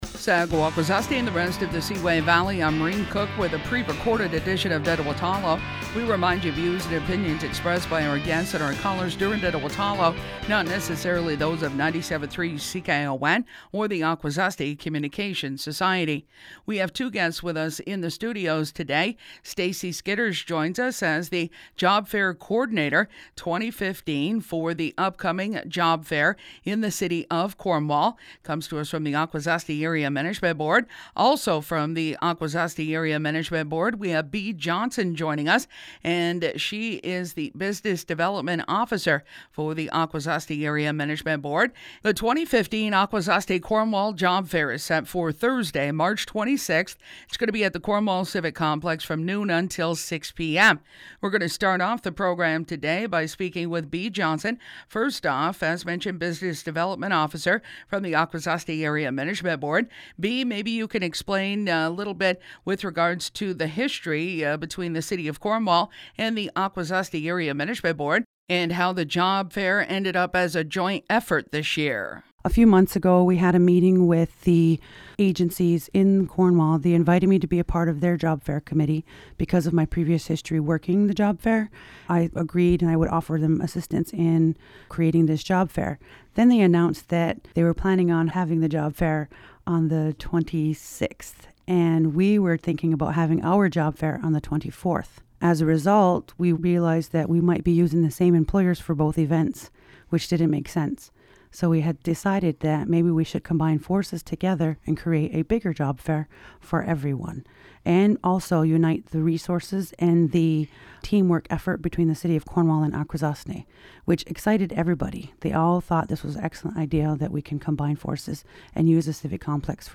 TalkSHOWjobFAIRfinalprerecorded.mp3